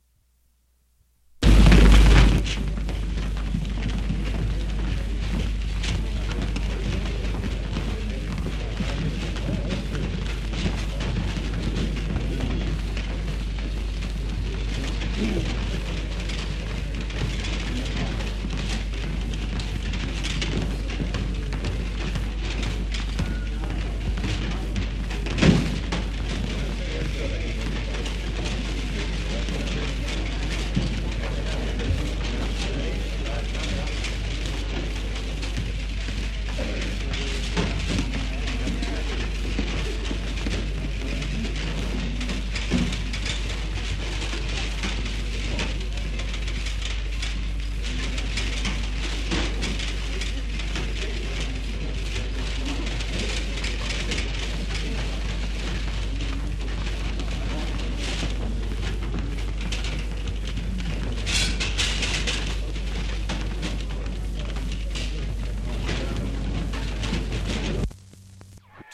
复古氛围 " G1608大型工厂氛围
描述：厂。一般温和的咆哮的设备。一些金属打击和电动马达。好响亮的哨声。 这些是20世纪30年代和20世纪30年代原始硝酸盐光学好莱坞声音效果的高质量副本。 40年代，在20世纪70年代早期转移到全轨磁带。我已将它们数字化以便保存，但它们尚未恢复并且有一些噪音。
标签： 工业 眼镜 复古 环境
声道立体声